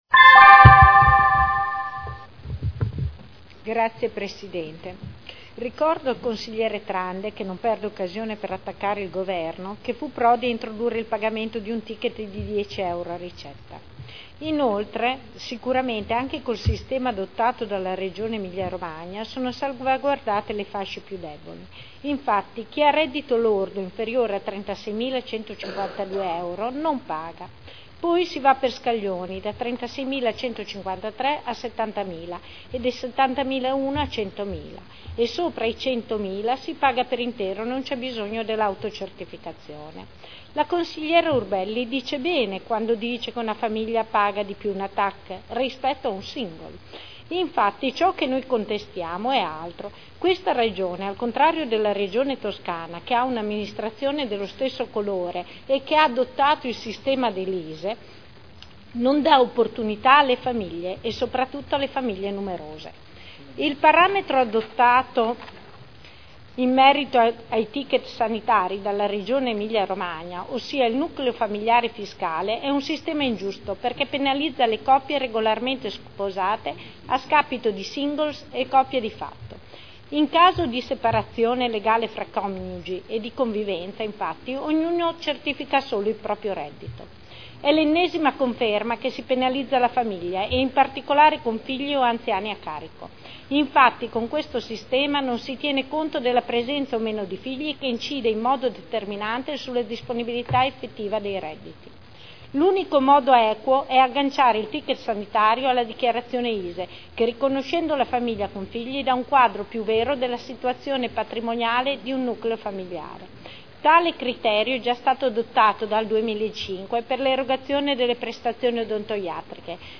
Luigia Santoro — Sito Audio Consiglio Comunale
Seduta del 12/09/2011. Dibattito su Ordini del Giorno relativi all'introduzione del ticket sanitario.